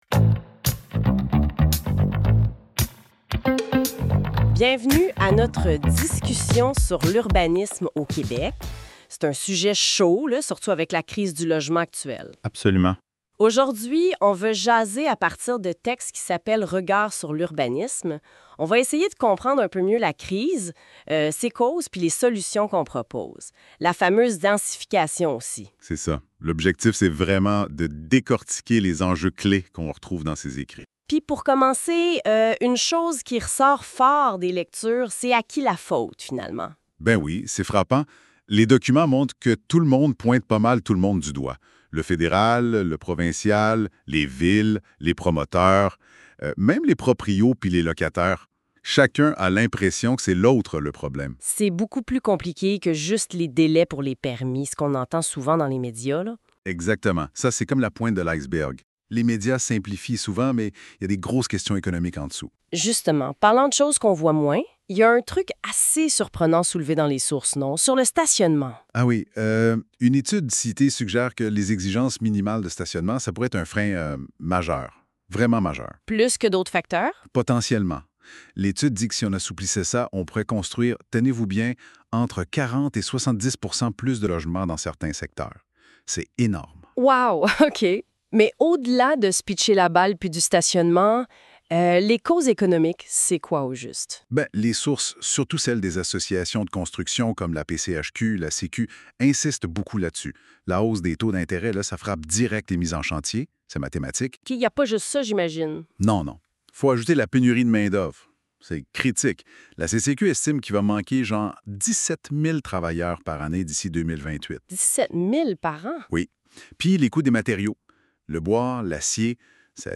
Ce podcast est généré par intelligence articifielle